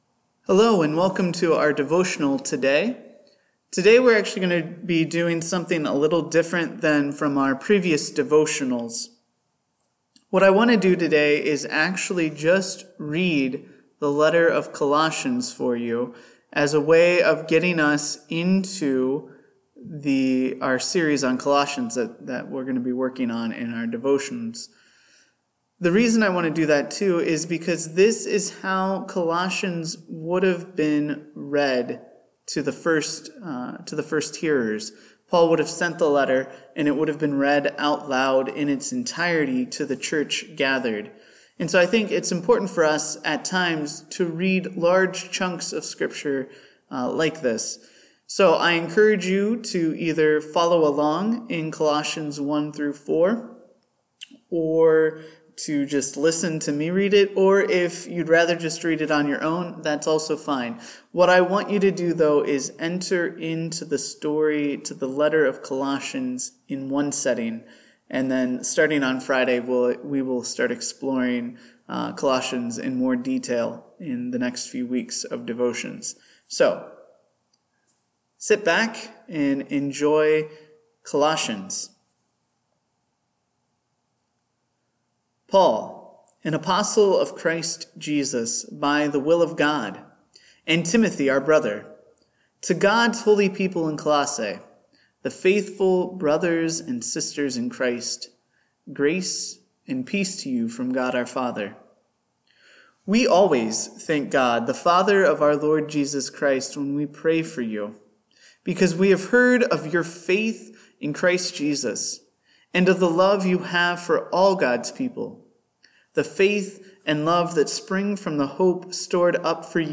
This devotional is a read through of the letter to the church at Colossae, like it would have been originally delivered. This will help us enter into a series of devotions from Colossians over the next few weeks.